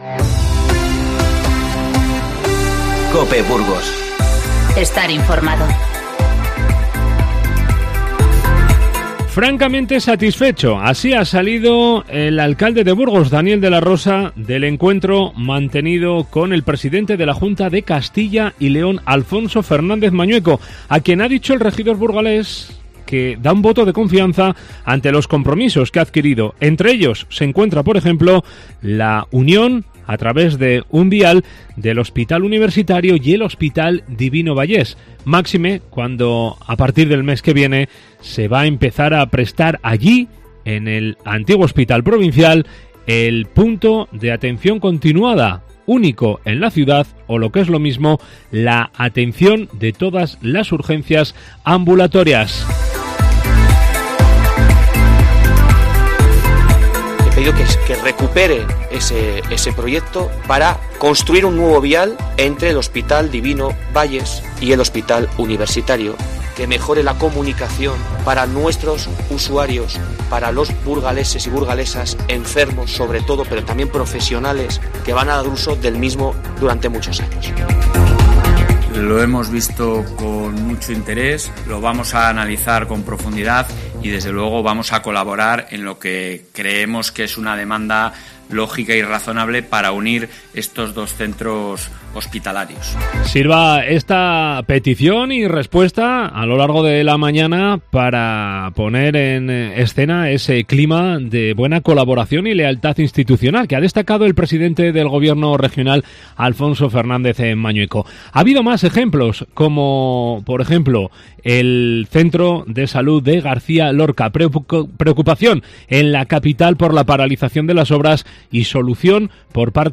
Informativo 26-02-20